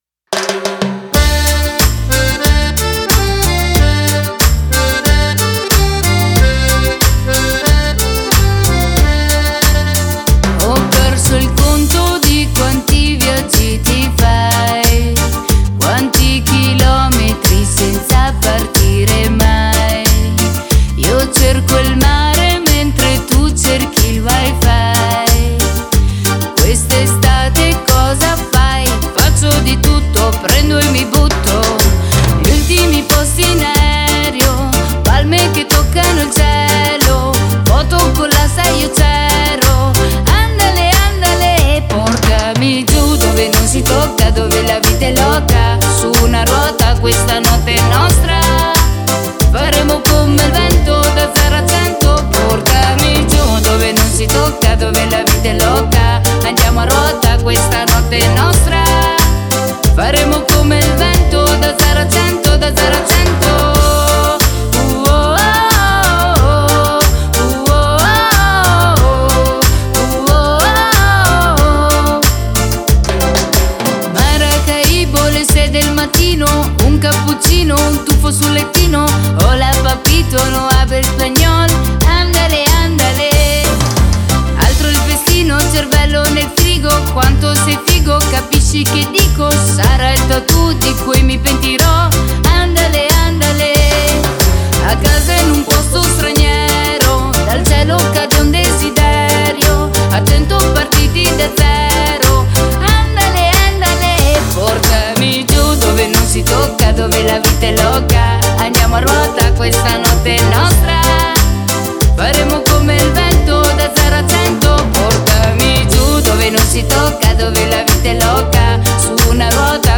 MIX I RACCONTI DI HOFFMANN cumbia ● I RACCONTI DI HOFFMANN